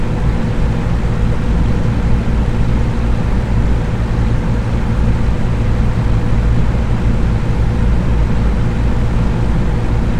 盒式风扇环境1(公寓)
描述：箱式风扇用挡风玻璃记录其不同的速度。
标签： 马达 风扇 氛围
声道立体声